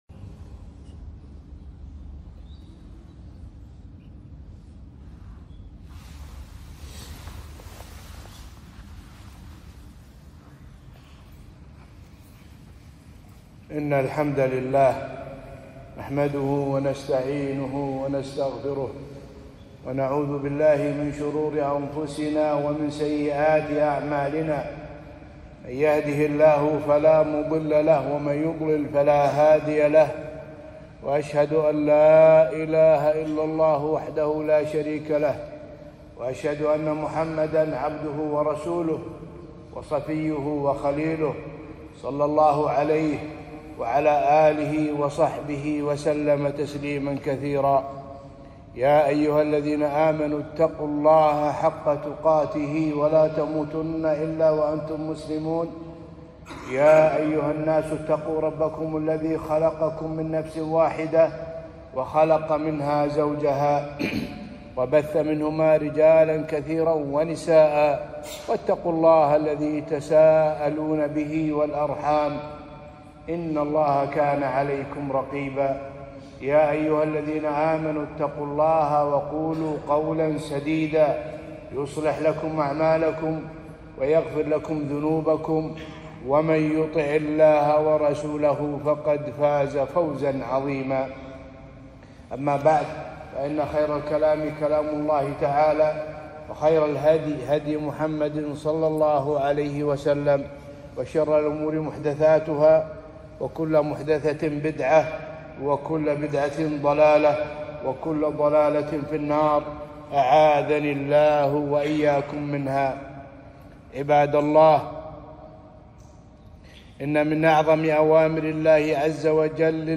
خطبة - بر الوالدين